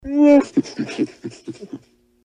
Звуки Чубакки